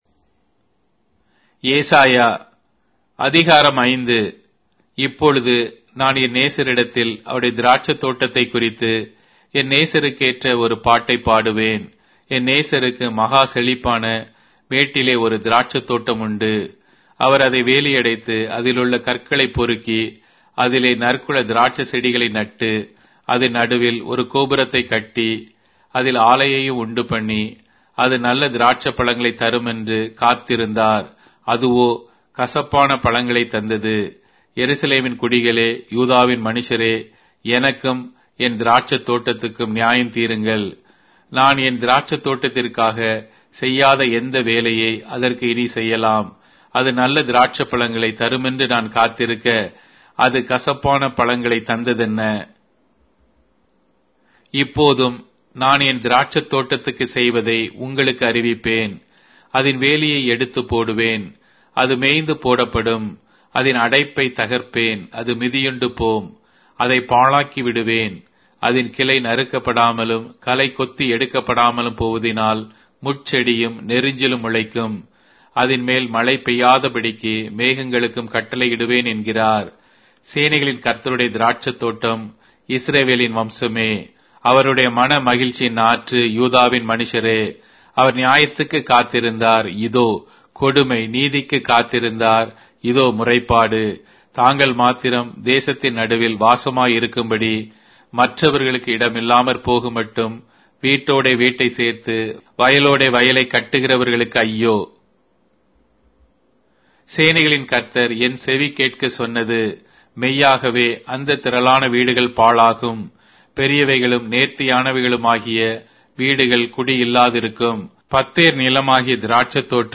Tamil Audio Bible - Isaiah 57 in Nlv bible version